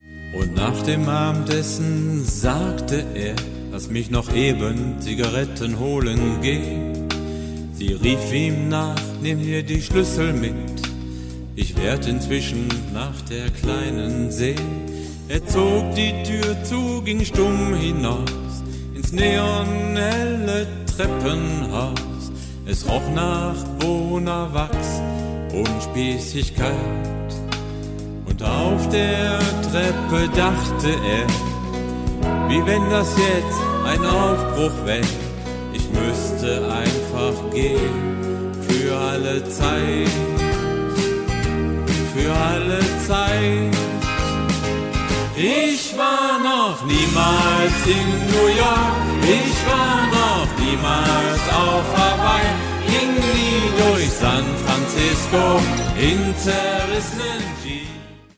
--- Oldies ---